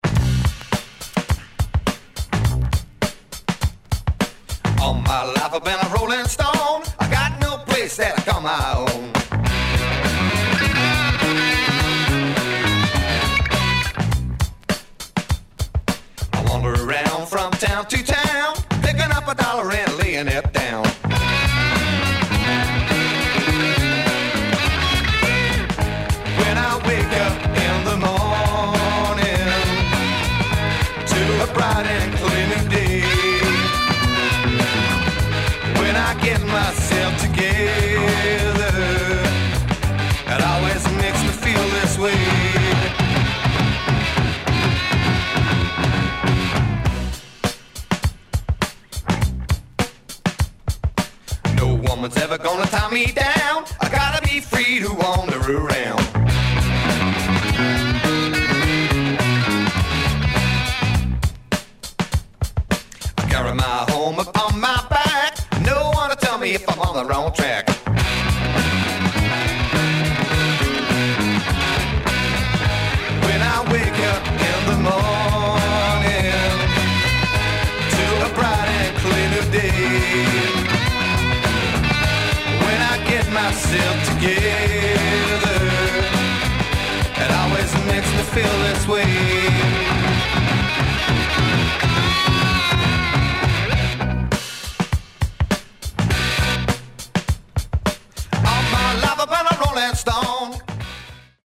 Drum Break!!